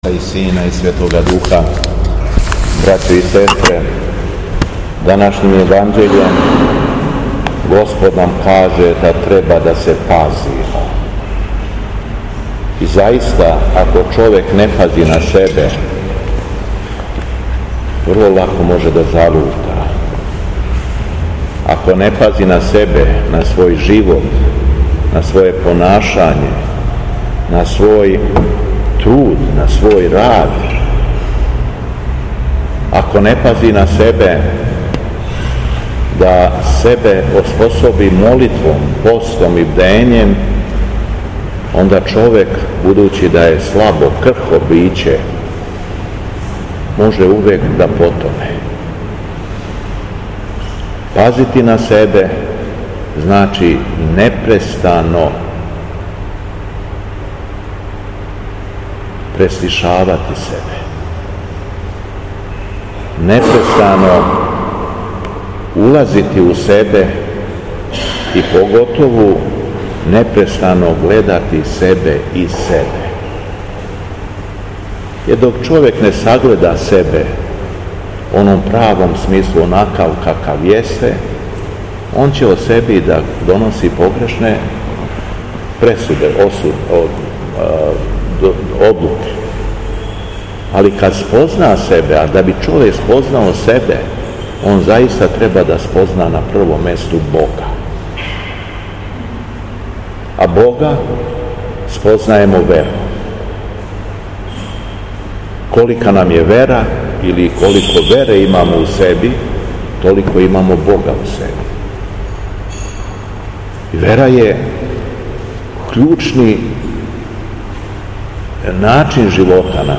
У понедељак четврти по Духовима, када наша Црква прославља свете мученике Мауила, Савела и Исмаила, Његово Високопреосвештенство Митрополит шумадијски Господин Јован служио је свету архијерејску литургију у храму Светога Саве у крагујевачком насељу Аеродром.
Беседа Његовог Високопреосвештенства Митрополита шумадијског г. Јована
По прочитаном Јеванђељу по Луки, Митрополит Јован обратио се верном народу надахнутом беседом: